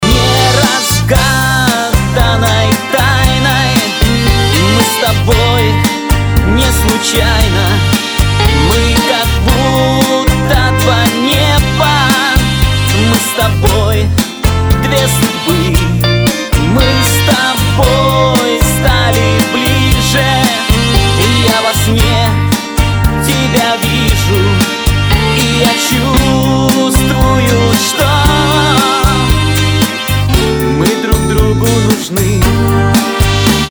• Качество: 320, Stereo
шансон